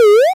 bubble.ogg